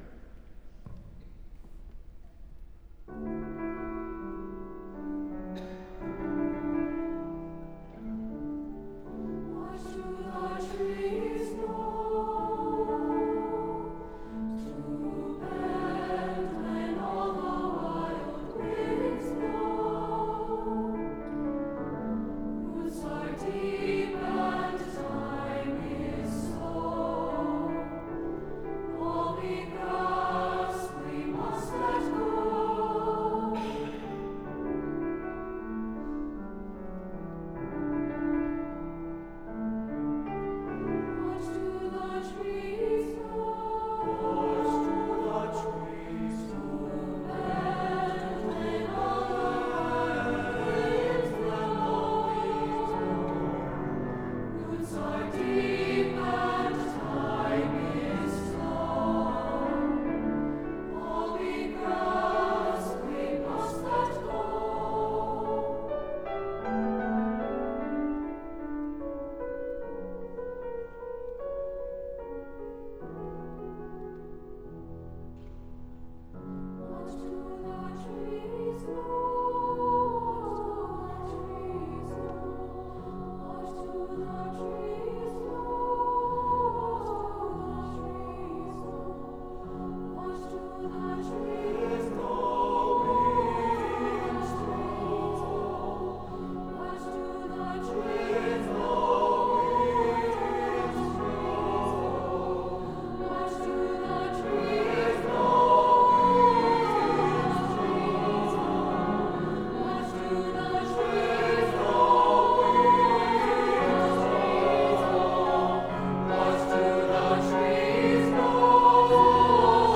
3-part mixed choir and piano